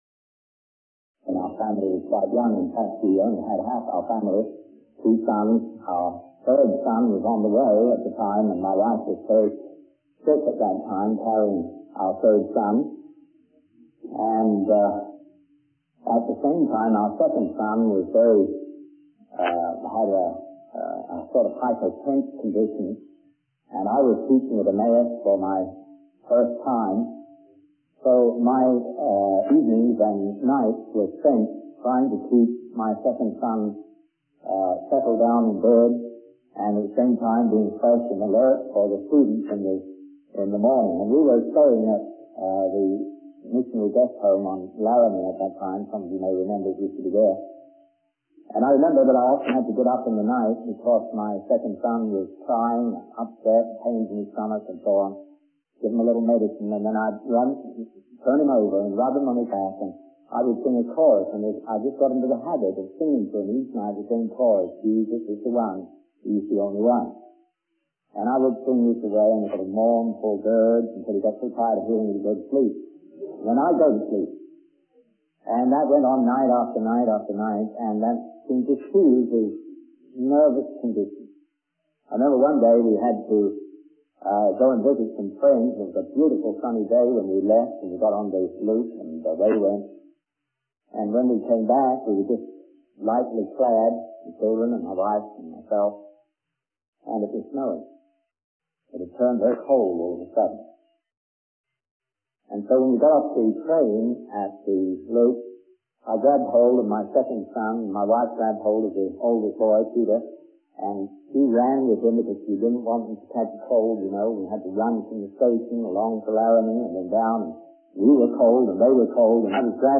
In this sermon, the speaker begins by using a metaphor of a mountain range to illustrate the journey of life and the challenges we face in understanding divine election and predestination. He acknowledges that there are aspects of these concepts that may be beyond our intellectual grasp, but emphasizes the importance of seeking and understanding what the Word of God teaches on the subject.